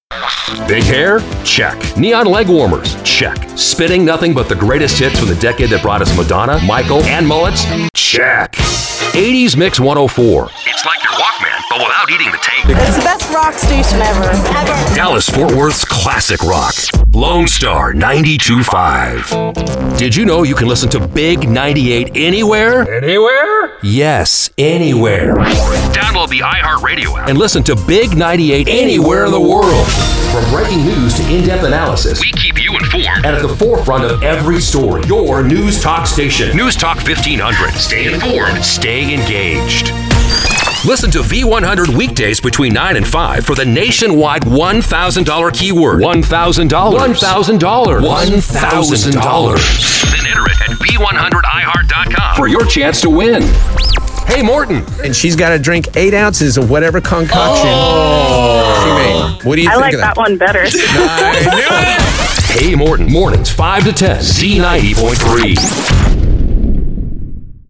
Male
Adult (30-50), Older Sound (50+)
I deliver a warm, friendly, relatable tone with clarity and articulation. I also possess a versatile and dynamic range that is energetic and enthusiastic, as well as authoritative and confident.
Radio / TV Imaging
Pop, Rock, Country, News